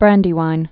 (brăndē-wīn)